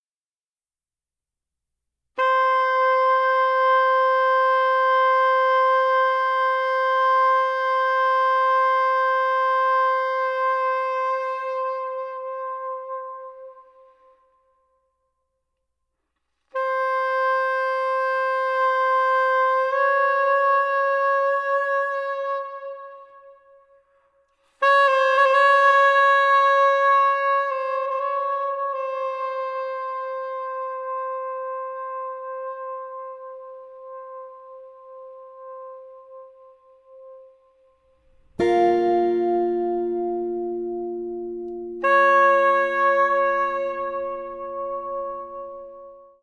Tenor and Soprano saxophones, Alto flute